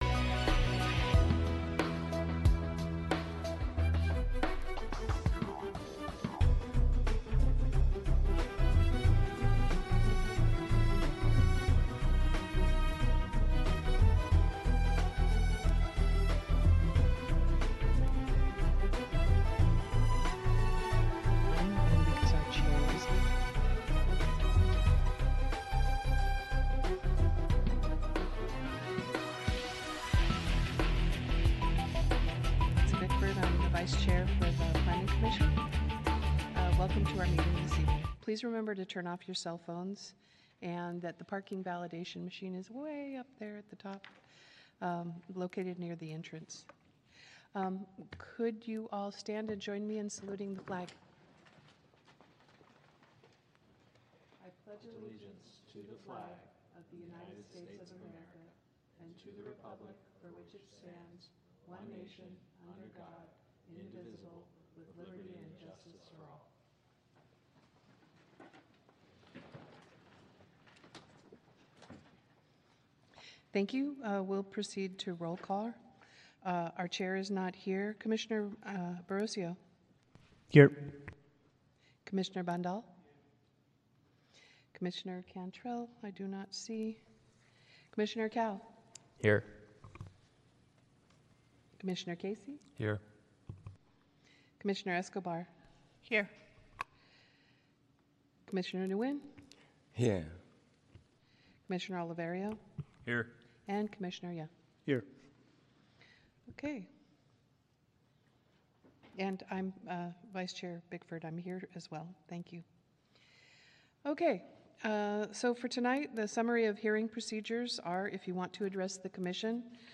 You'll hear authentic audio capturing the voices of city officials, community leaders, and residents as they grapple with the local issues of the day. This podcast serves as an archival audio record, providing transparency and a direct line to the workings of local government without editorial polish.